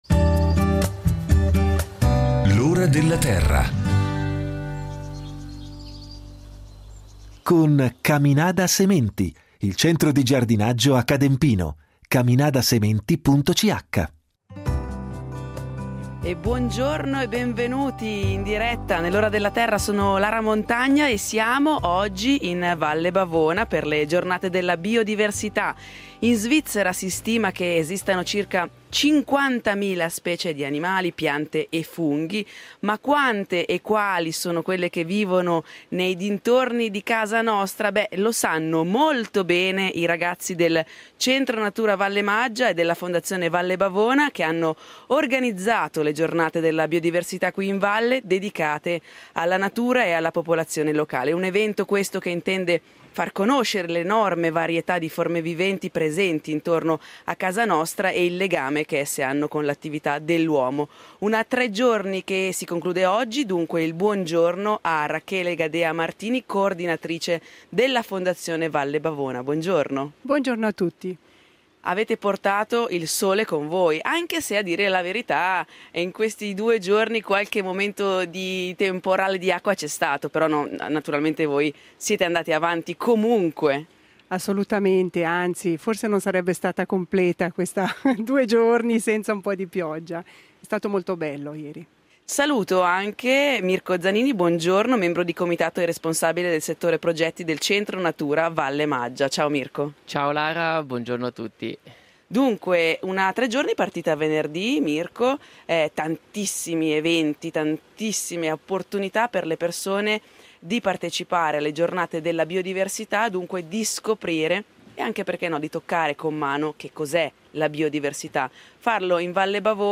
L’Ora della Terra sarà in diretta da Sabbione